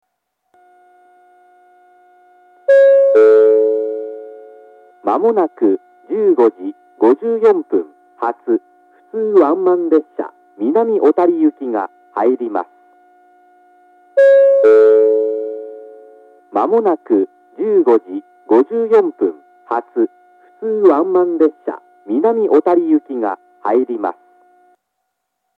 ２番線接近予告放送 15:54発普通ワンマン南小谷行の放送です。